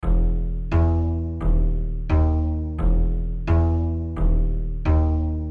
Tag: 87 bpm Hip Hop Loops Harp Loops 950.47 KB wav Key : Unknown FL Studio